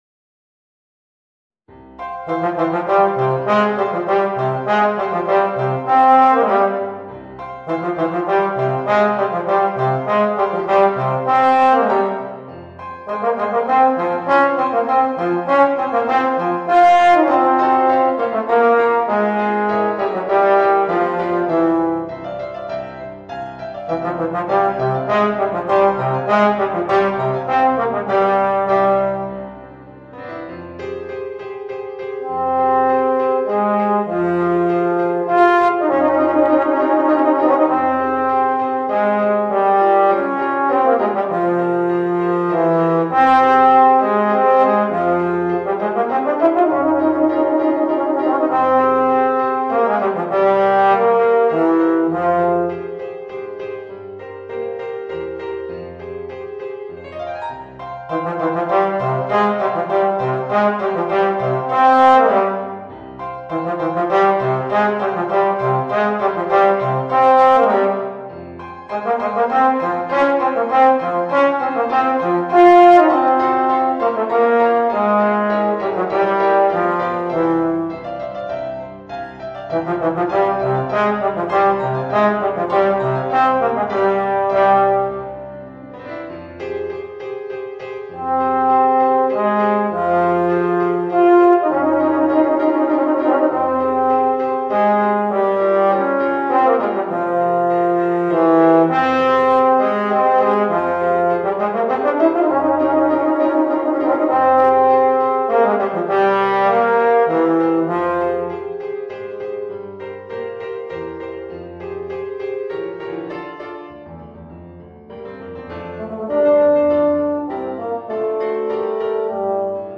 Voicing: Euphonium and Piano